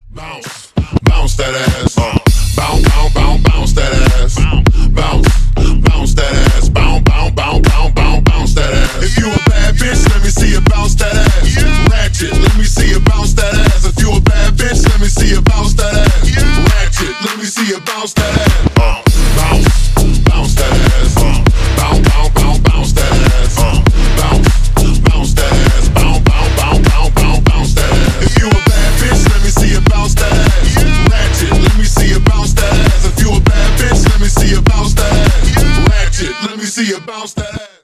Танцевальные
клубные # громкие